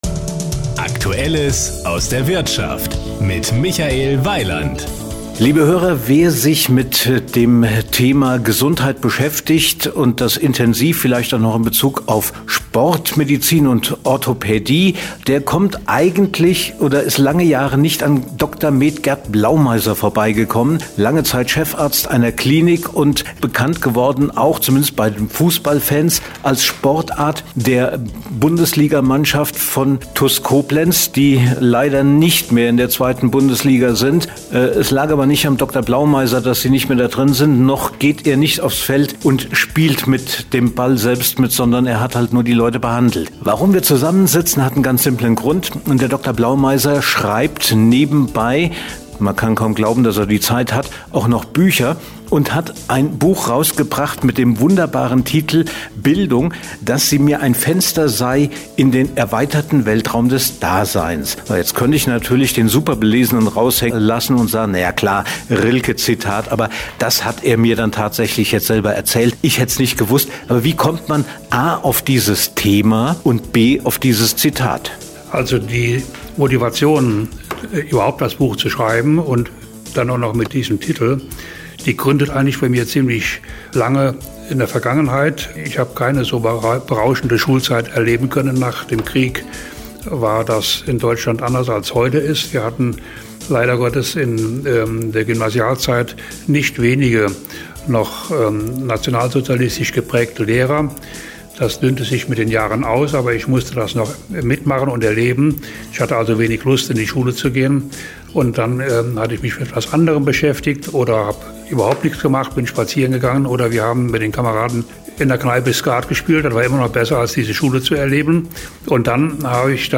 Sie sind hier: Start » Interviews » Interviews 2019